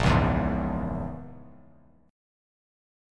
Bbm7b5 chord